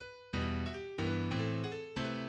No. 5 E minor